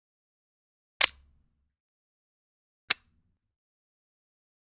Camera Manual, Snap, Long x2